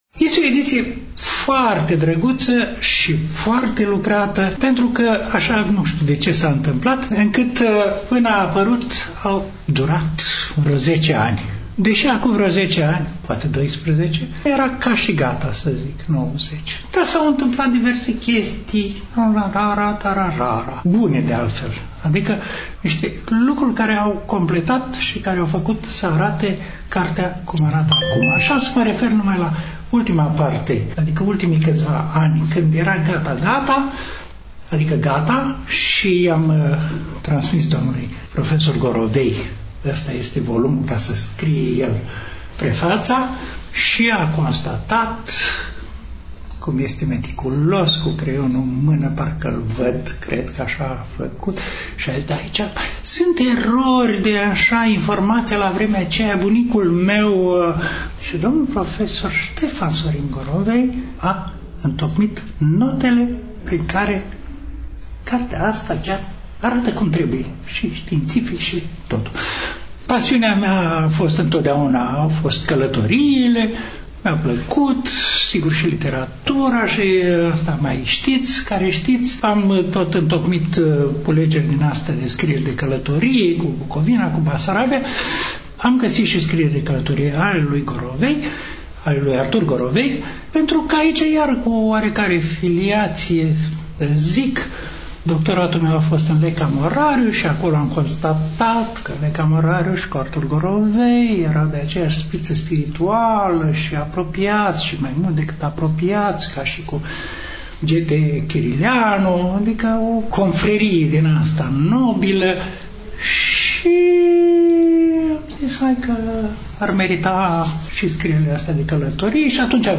Stimați prieteni, în următoarele minute, relatăm de la prezentarea cărții Vânturând țara, de Artur Gorovei, carte lansată, la Iași, nu demult, în incinta Editurii Junimea.